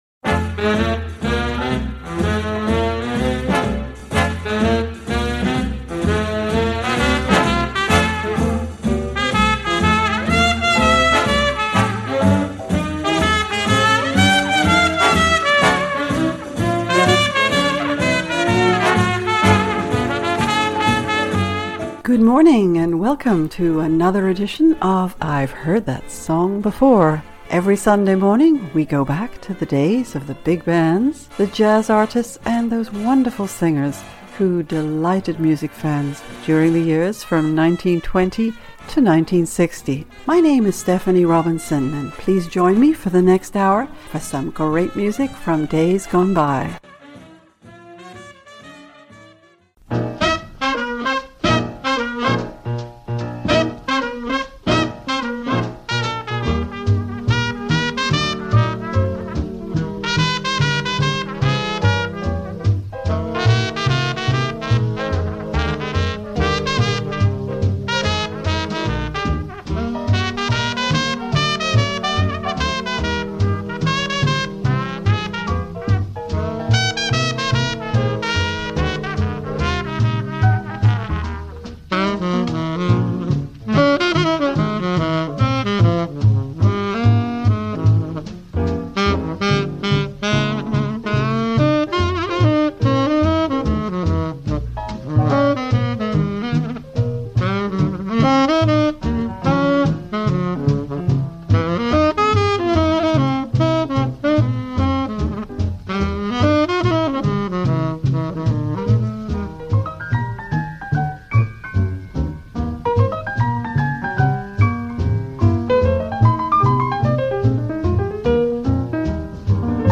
We hear songs from several big bands